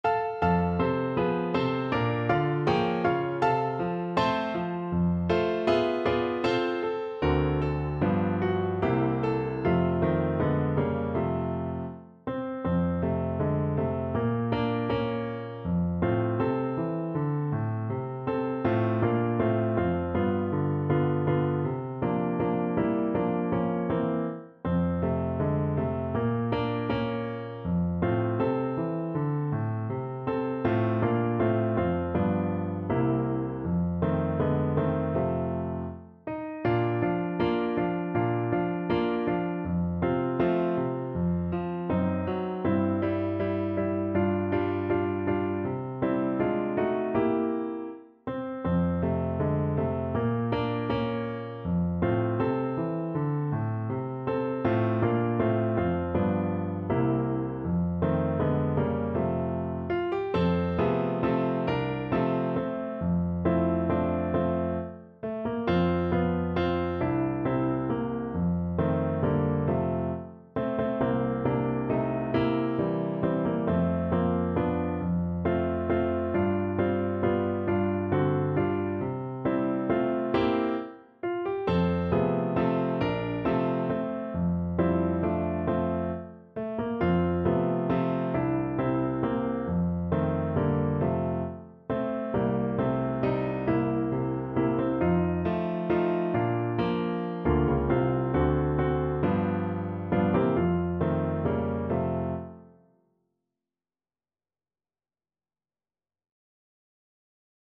Andante
4/4 (View more 4/4 Music)